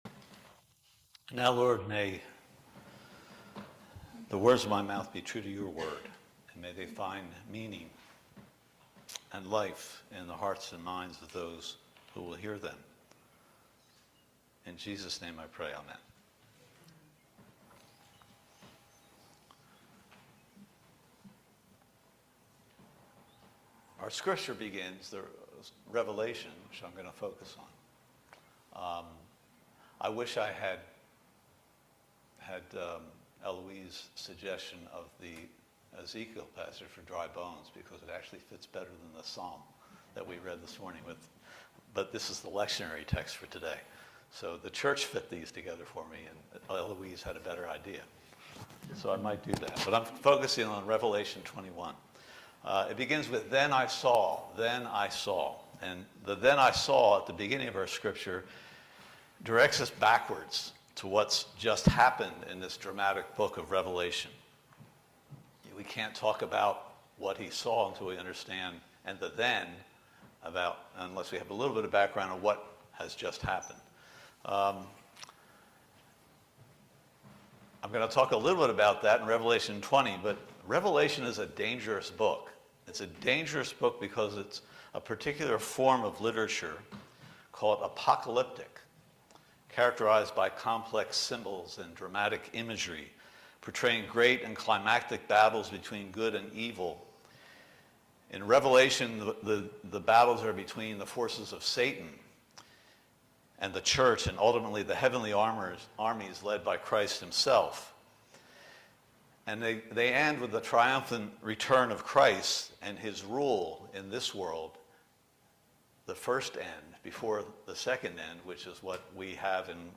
Revelation 21:1-6 Service Type: Sunday Service That all of this stuff of the church